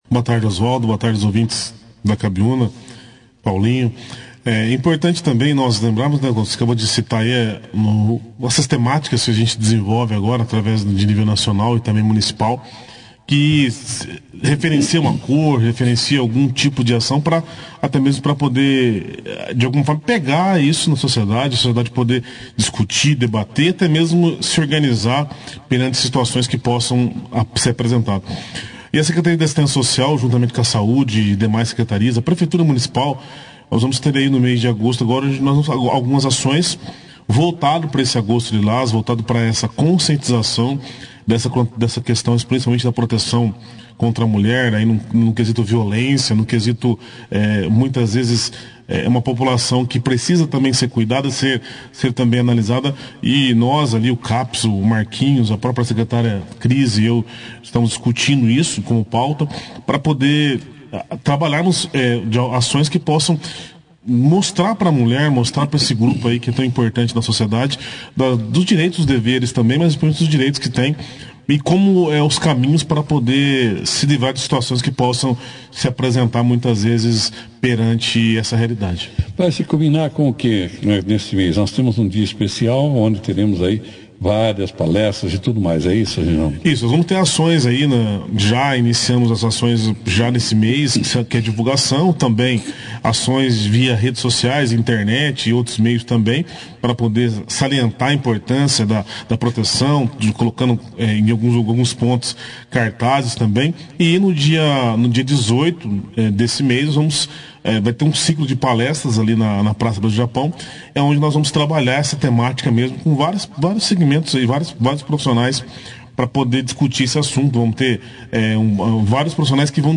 Secretário de Assistência Social fala sobre campanha "Agosto Lilás", jantar do Dia dos Pais e de novos cursos através do Senac em Bandeirantes - Rádio Cabiuna
entrevista-reginaldo-07_08.mp3